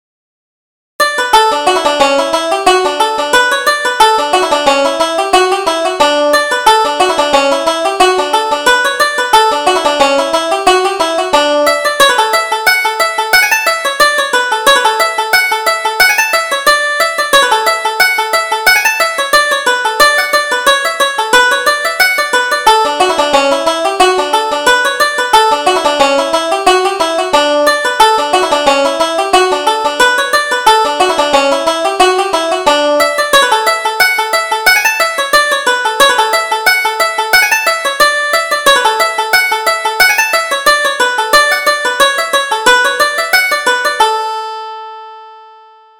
Reel: Push about the Jorum